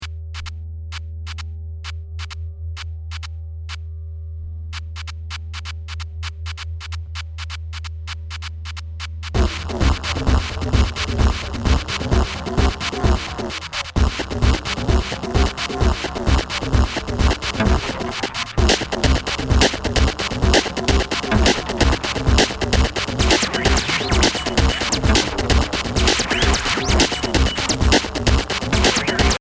Sounds, rhythmic noise, and atmospheric constructions.
This is an instrumental disc with 1 spoken word track.